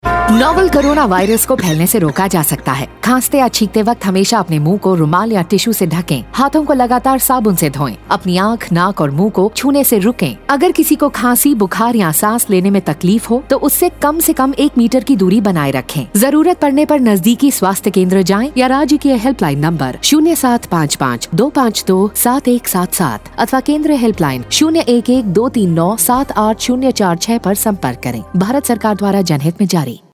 Radio spot on key behaviours COVID-19_Hindi_Madhya Pradesh
Radio PSA
5144_Cough Radio_Hindi_MP.mp3